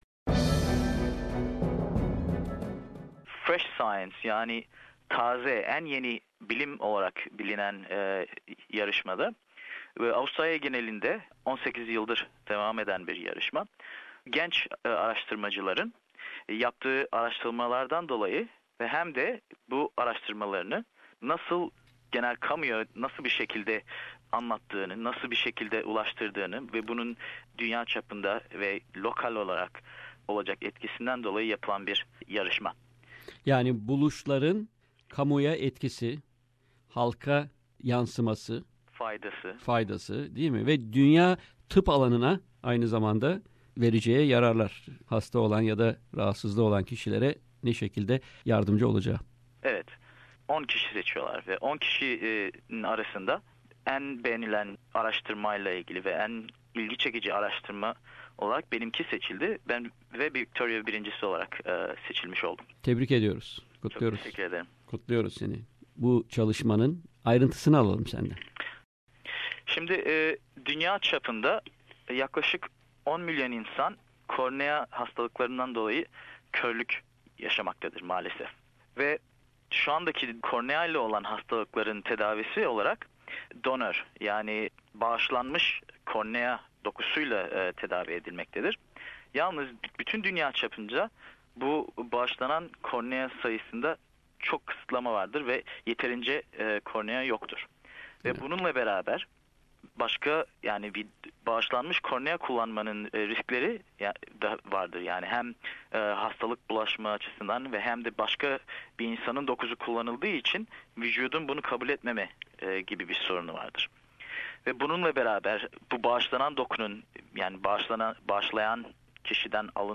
yaptığımız söyleşinin kısa bir bölümünü dinleyebilirsiniz.